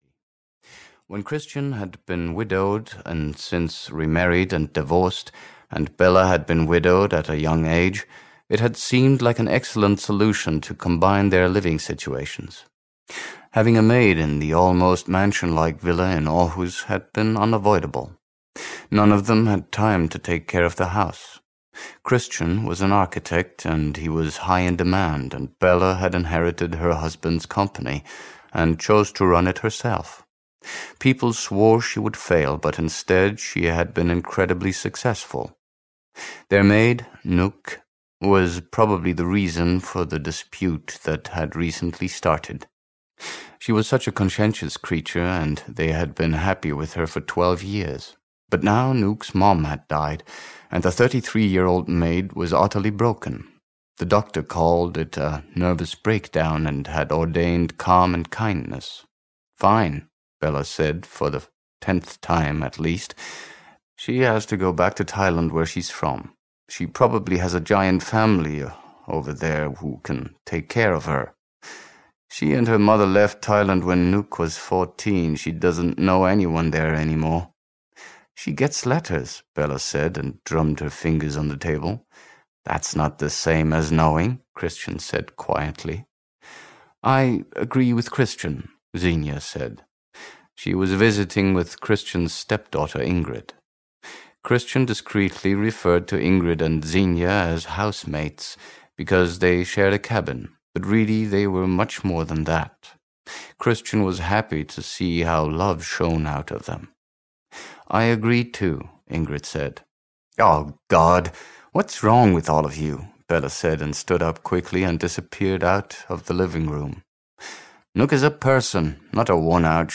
Ukázka z knihy
cabin-fever-5-complicated-caution-en-audiokniha